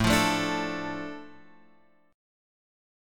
A Suspended 4th Sharp 5th